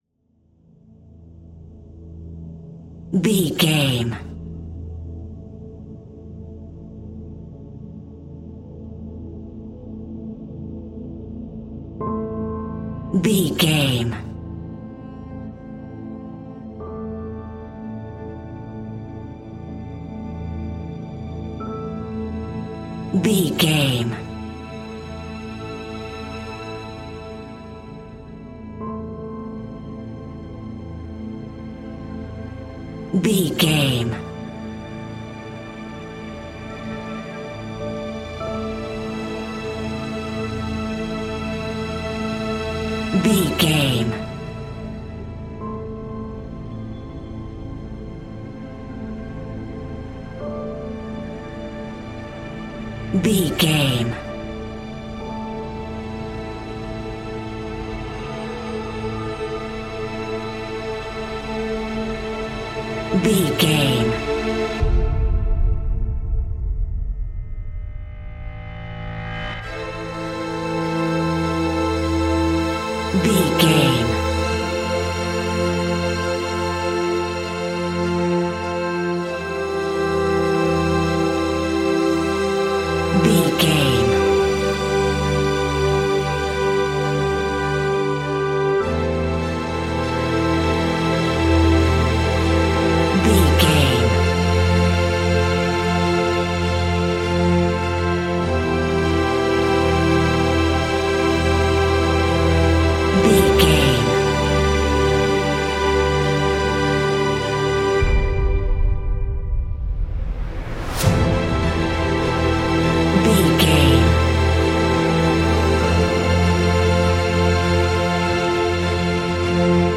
Ionian/Major
epic
driving
energetic
hopeful
powerful
uplifting